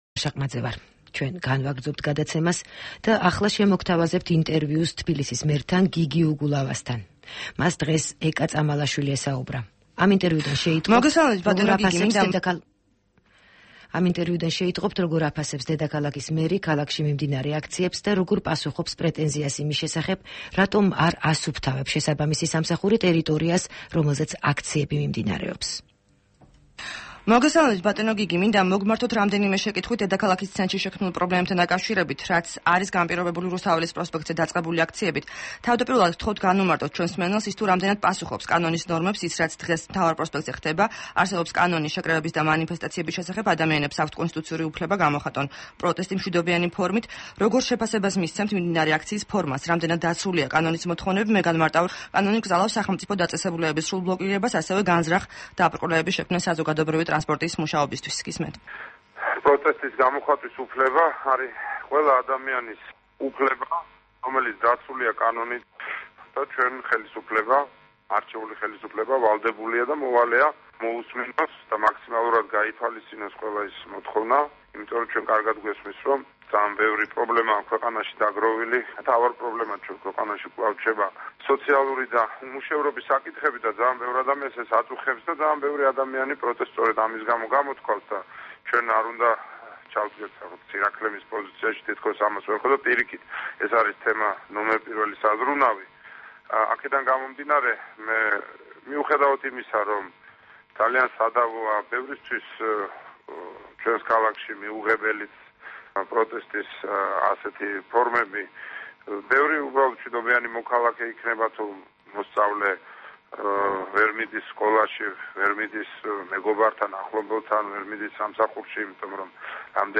ინტერვიუ გიგი უგულავასთან